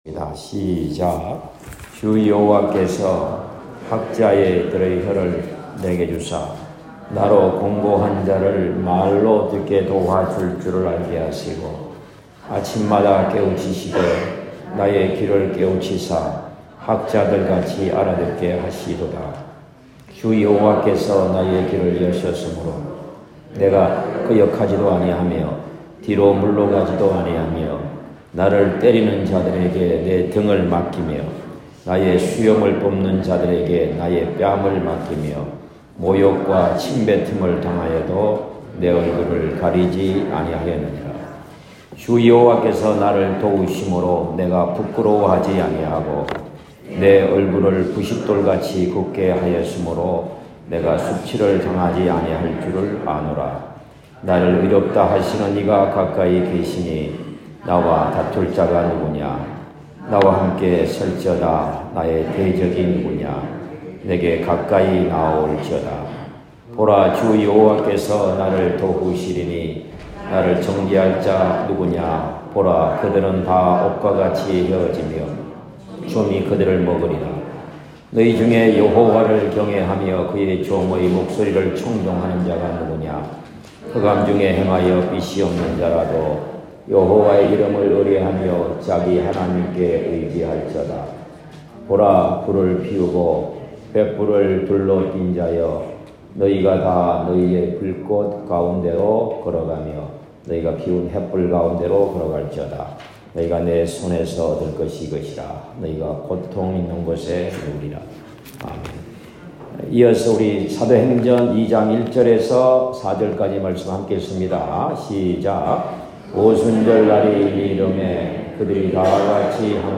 2023년 4월 23일 주일설교(2부)